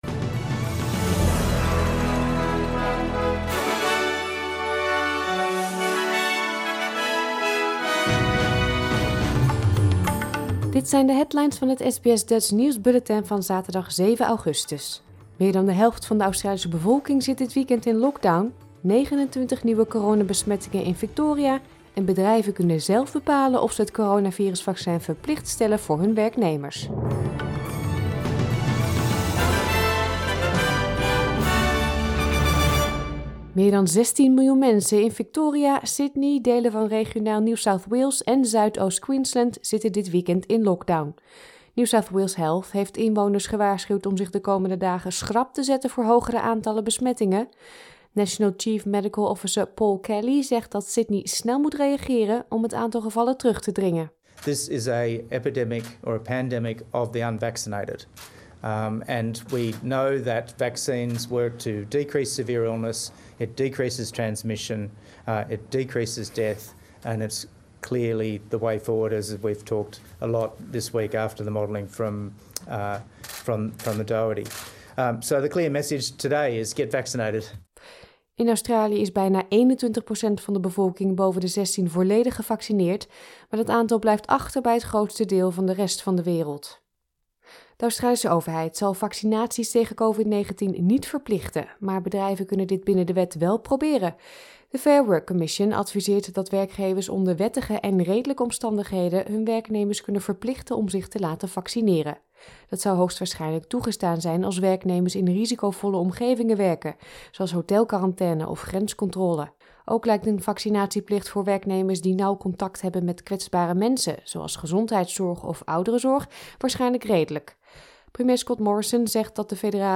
Nederlands/Australisch SBS Dutch nieuwsbulletin van zaterdag 7 augustus 2021